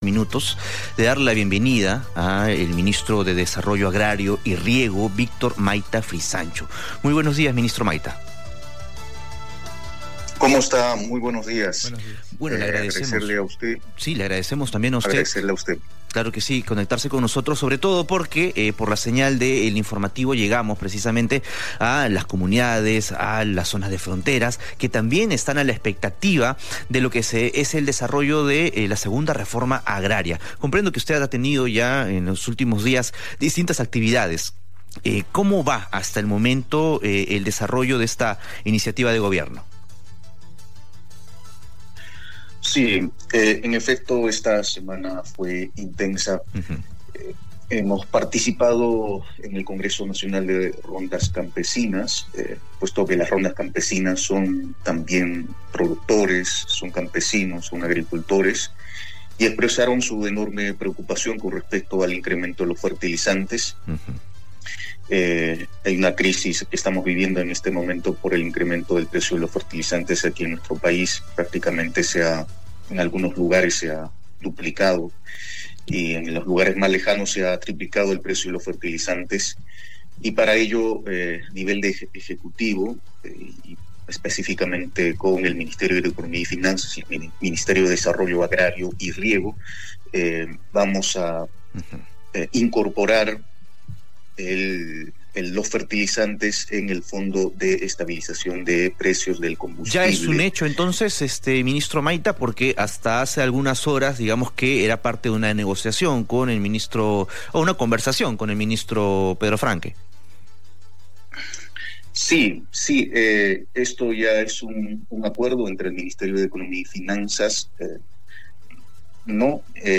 Entrevista al ministro de Desarrollo Agrario y Riego